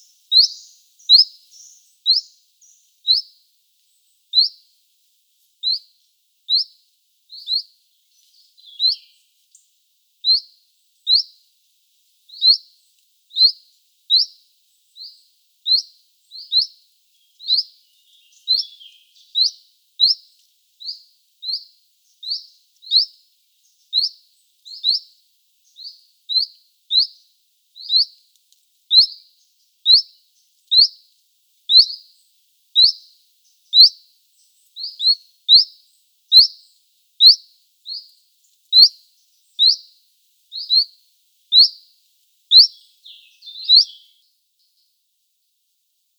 Phylloscopus collybita
Nearby there is a second Chiffchaff which calls too.
- SEX/AGE: adult bird sex unknown - COMMENT: The call series of this bird shows a quick repetition rate, a quite short call duration and an anxious behaviour.